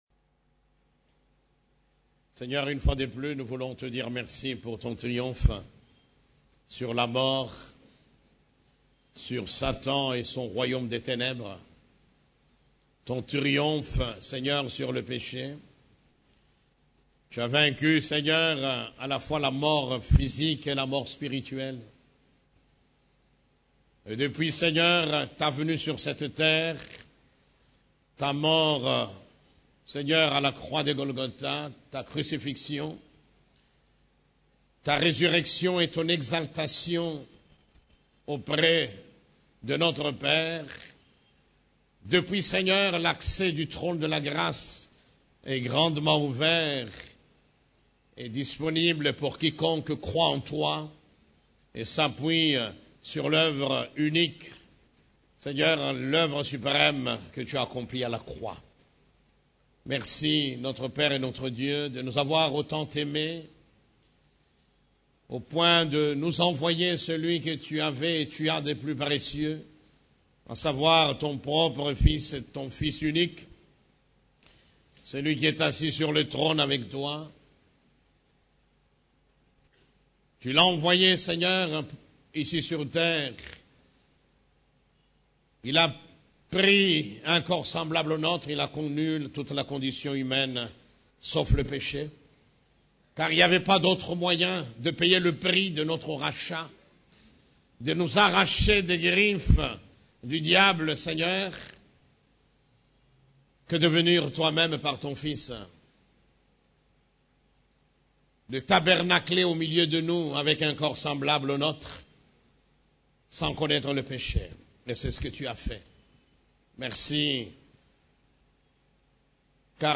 CEF la Borne, Culte du Dimanche, Pourquoi se tourmenter ?